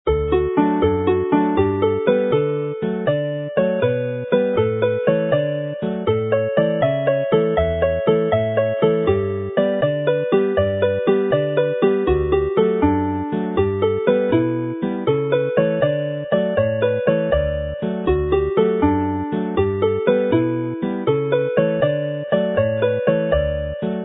jig